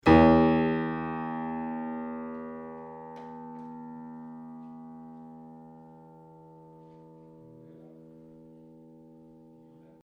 I campioni sonori che seguono sono stati registrati subito dopo il montaggio dei martelli (originali e replicati), sulle rispettive meccaniche (ottobre 2002): il suono dei martelli originali (nei quali le pelli sono solcate e un po' consunte alla sommità), è come facilmente prevedibile leggermente più pungente, di quello dei martelli replicati, al momento della registrazione intatti e del tutto privi di solchi.
Ascolta MI 1 (martello originale), quarto livello di sollecitazione meccanica